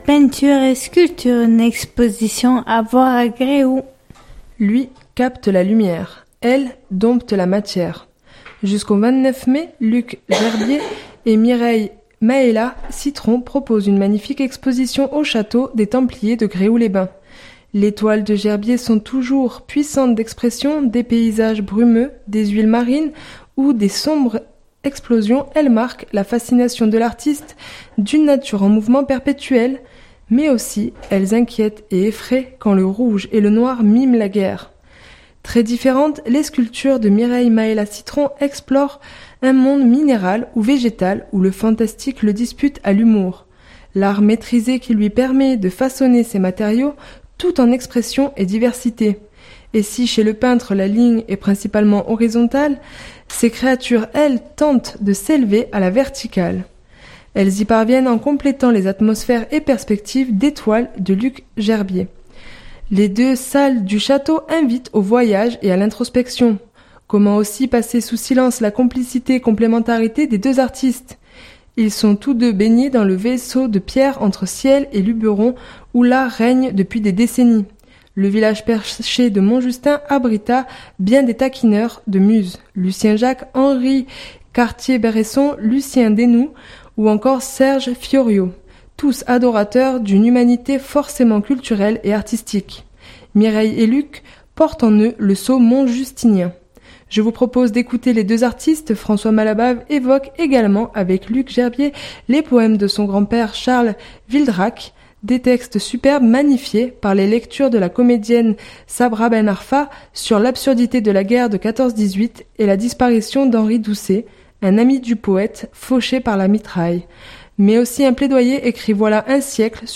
Je vous propose d’écouter les deux artistes.